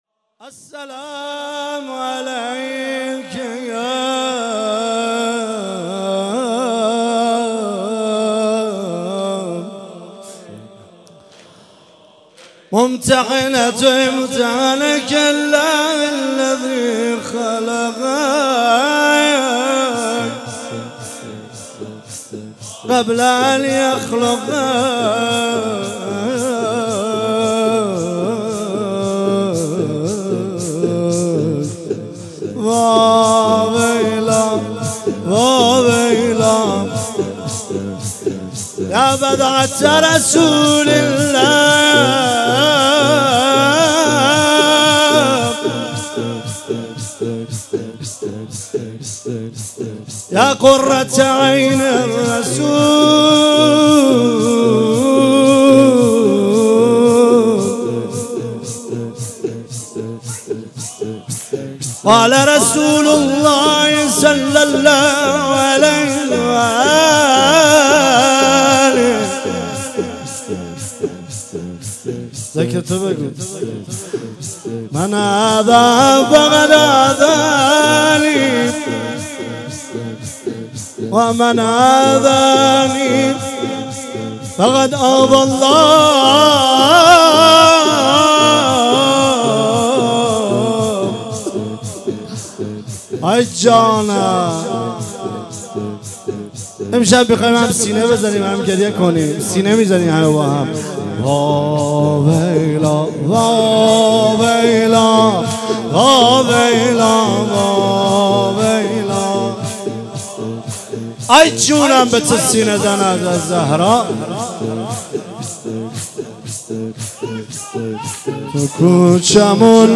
فاطمیه 96 - 10 بهمن - دامغان - زمینه - تو کوچمون بلوا بود
فاطمیه